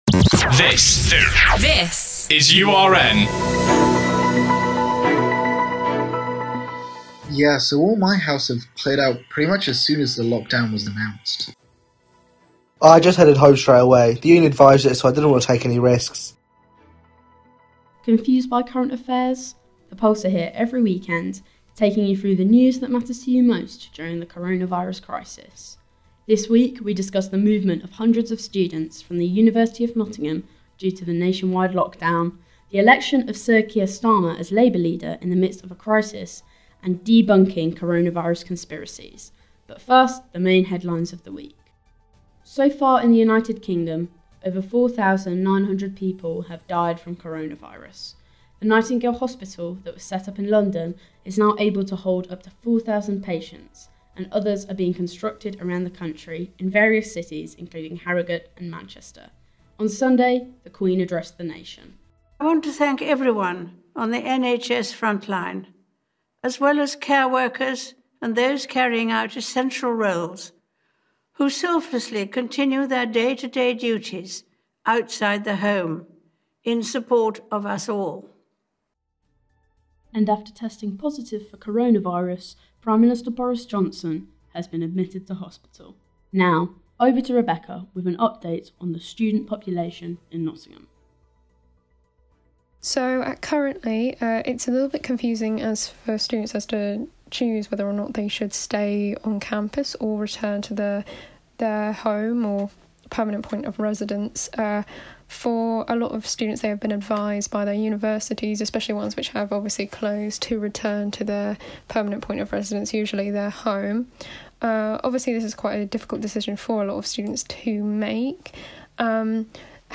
The URN news team, The Pulse, take you through this week's current affairs. On today's episode, the team discuss students leaving Nottingham during lockdown, the Labour leadership election, and Coronavirus conspiracy theories.